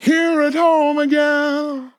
Categories: Vocals Tags: again, english, fill, FORM, here, home, it, LOFI VIBES, male, sample, wet
MAN-LYRICS-FILLS-120bpm-Am-11.wav